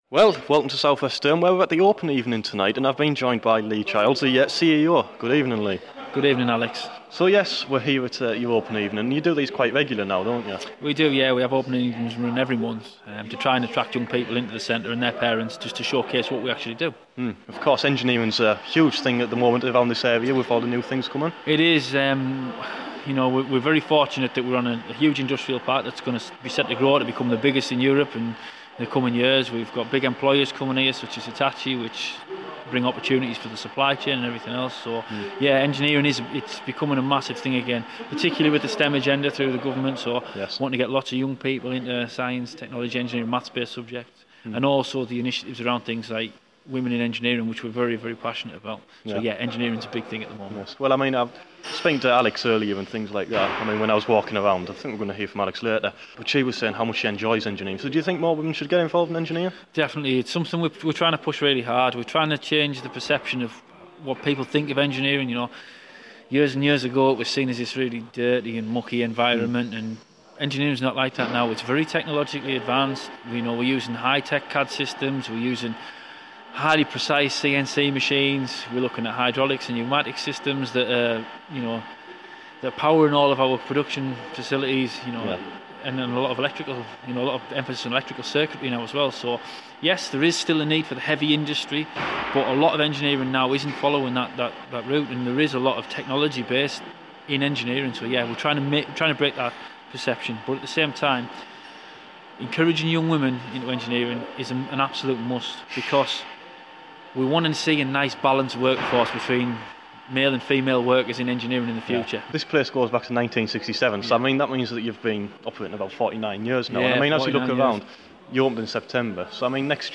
South West Durham Training in Newton Aycliffe held an open evening during National Apprenticeship Week.